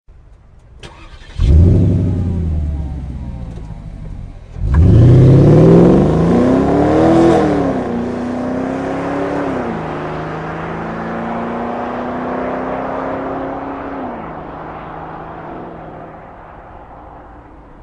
porsche-cayenne-turbo-s_24966.mp3